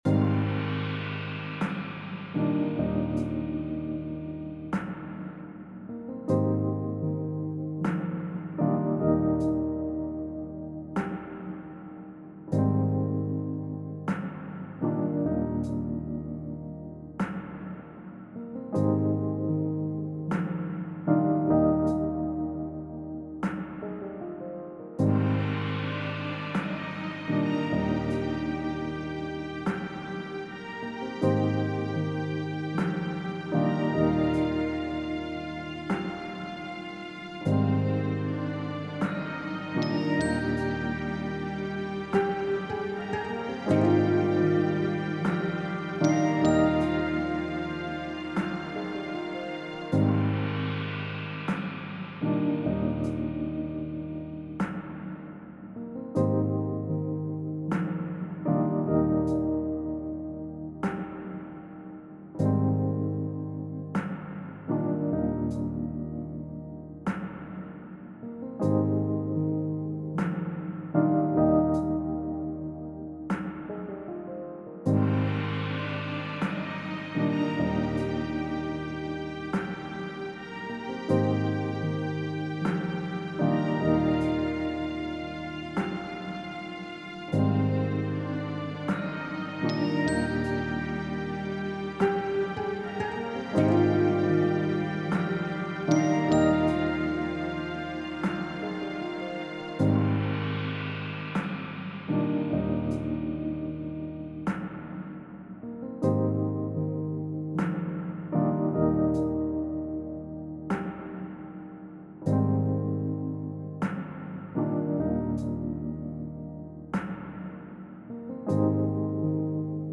Untitled EP Production My upcoming debut EP blends ambient soundscapes, and a genre concoction of Afrobeat, Highlife, Neo-soul, Rock, and Jazz to tell a deeply personal feeling and story. Built from field recordings, dreamy arrangements and layered vocals, the project explores emotional themes like vulnerability, fearful avoidance, and self-reflection. Each track is designed to evoke a scene or feeling dragging the listener from the quiet tension of caution to the warmth of connection, while maintaining a cohesive sonic atmosphere. I utilized a Zoom H1 recorder for original environmental sounds, capturing everyday moments like footsteps, laughter, traffic hums, and clinking coffee mugs.